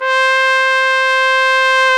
Index of /90_sSampleCDs/Roland L-CDX-03 Disk 2/BRS_Trumpet 1-4/BRS_Tp 1 Class